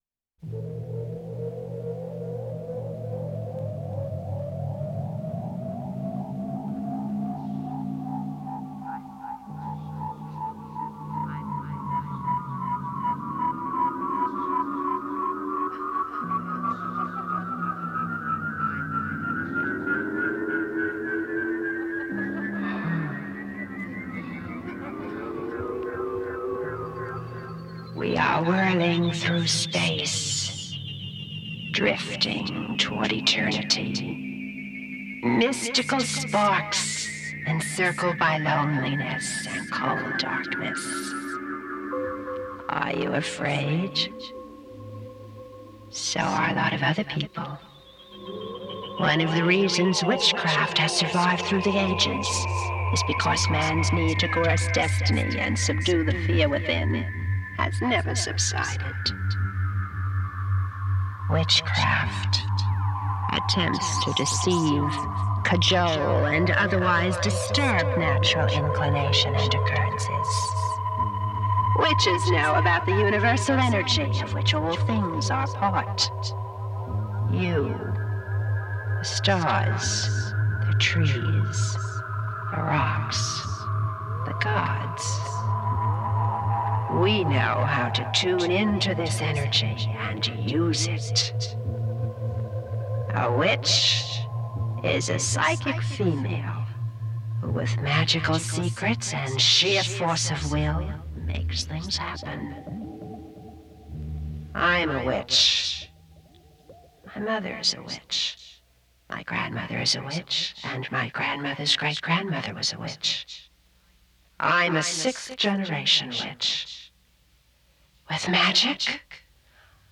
orchestration cybernétique